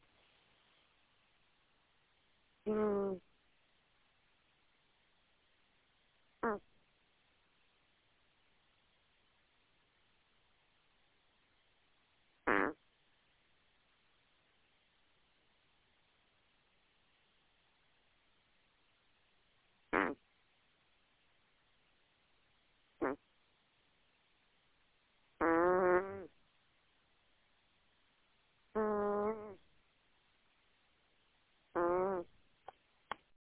Cat Snoring, Smoky (2010)
smoky-the-cat-snoring-2010.mp3